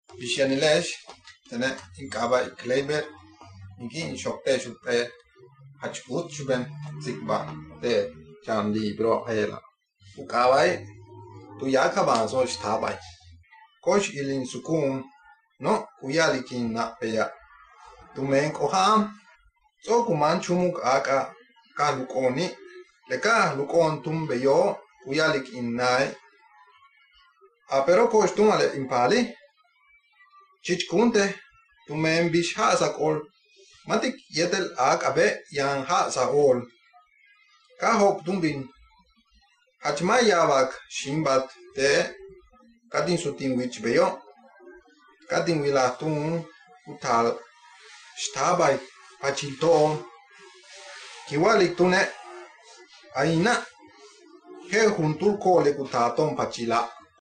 Here’s a recording in a mystery language, which features someone reading part of a story.